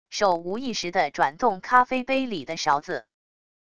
手无意识的转动咖啡杯里的勺子wav音频